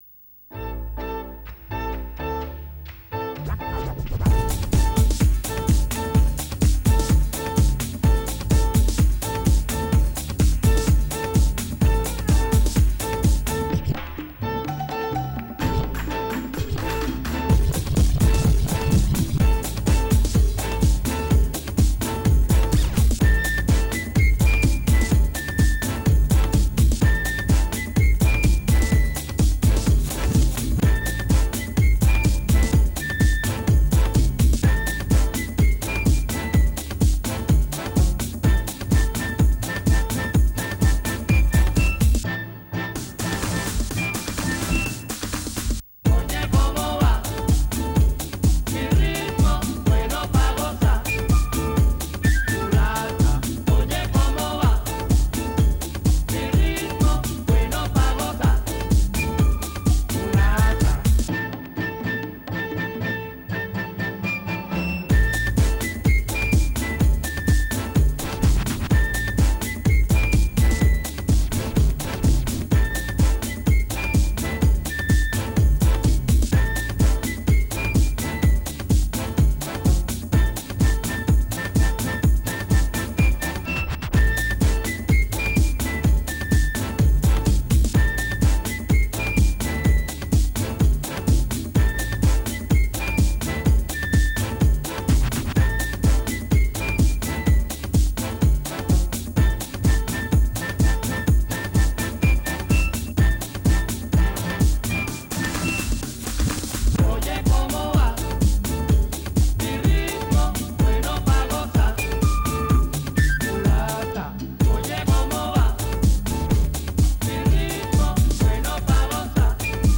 latino house